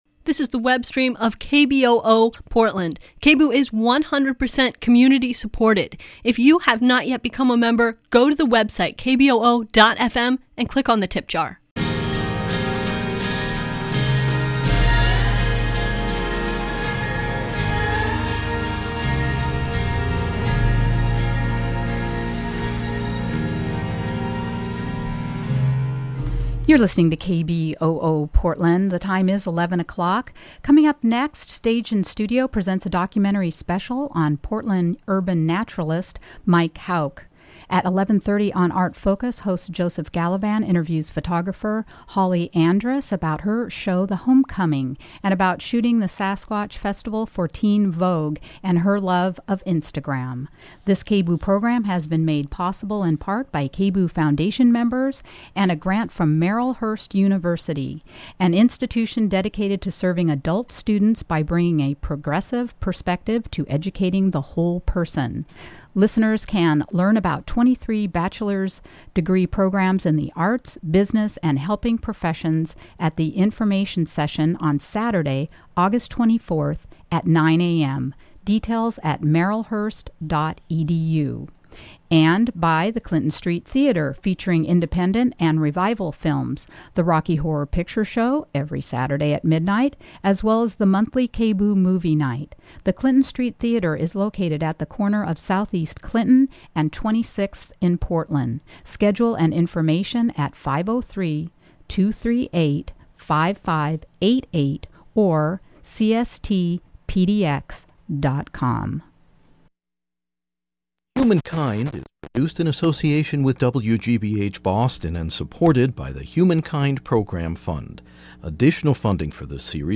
We'll also hear music from Third Angle New Music Ensemble preparing for their TBA performances.